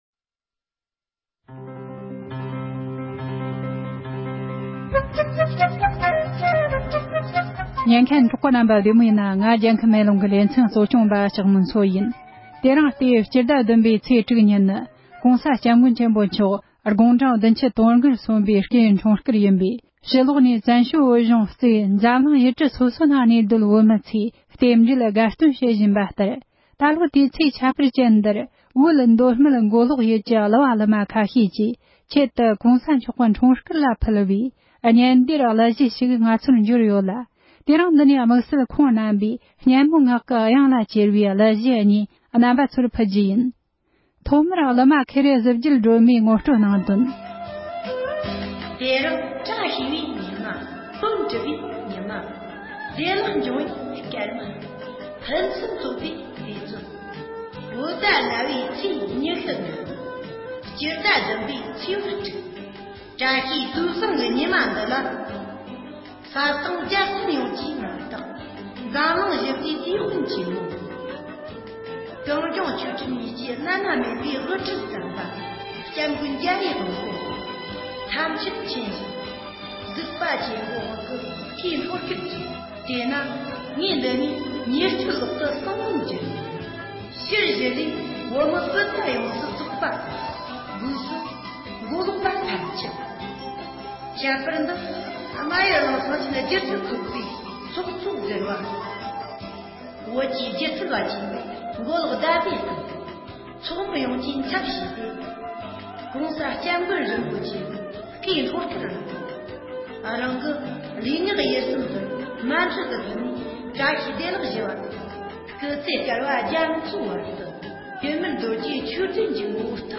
མདོ་སྨད་མགོ་ལོག་ཁུལ་གྱི་གླུ་བ་གླུམ་ཁག་ཅིག་གིས་༸གོང་ས་༸སྐྱབས་མགོན་ཆེན་པོ་མཆོག་གི་སྐུའི་འཁྲུངས་སྐར་ལ་ཕུལ་བའི་གླུ་གཞས།
སྒྲ་ལྡན་གསར་འགྱུར།